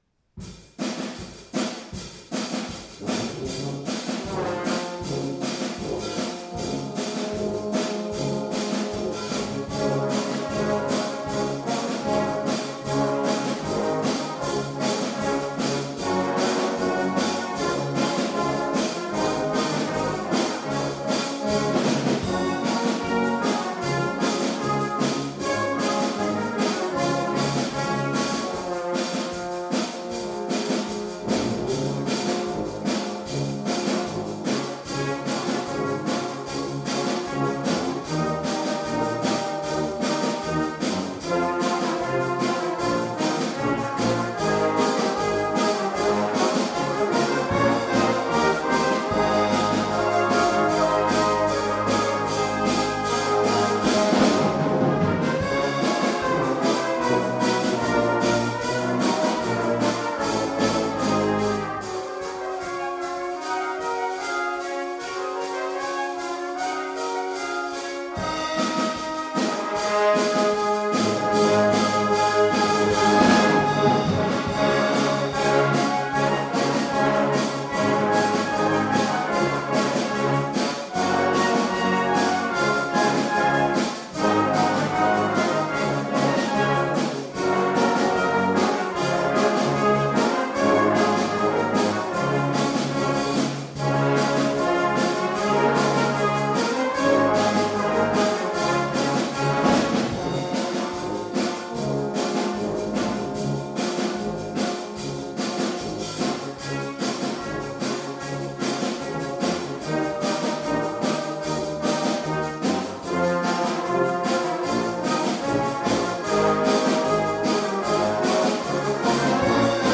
The following pieces were recorded at our concert at Foxearth Church on 19th September 2015